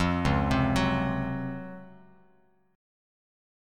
C#M11 Chord
Listen to C#M11 strummed